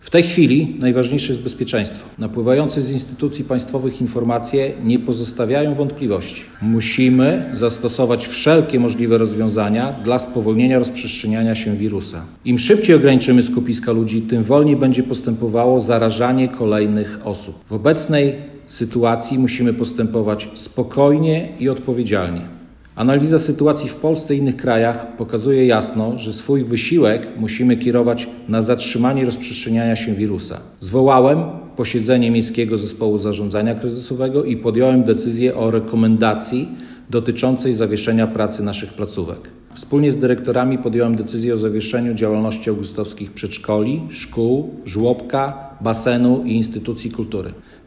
Podobne działania podejmują władze Augustowa, które zdecydowały o zamknięciu miejskiego basenu, żłobka, przedszkoli oraz placówek kultury. O szczegółach Mirosław Karolczuk, burmistrz Augustowa.